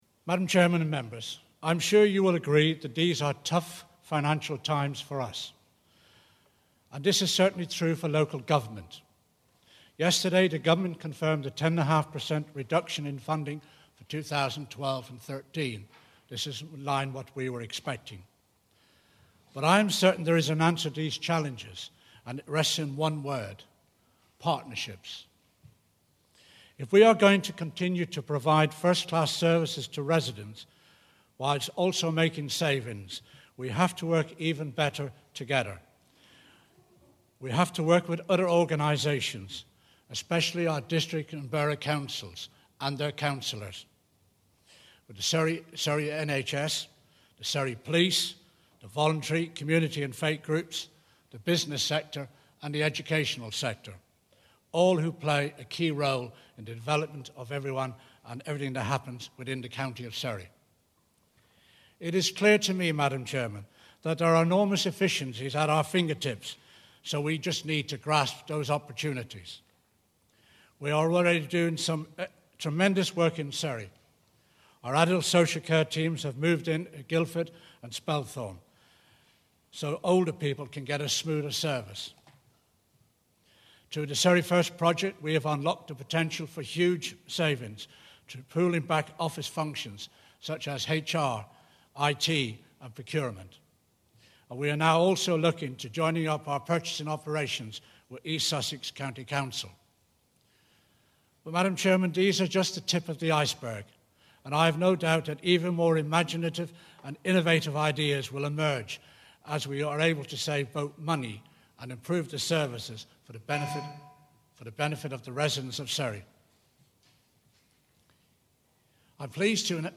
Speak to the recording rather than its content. Below is a transcript of the speech to Full Council, given on 13 December 2011.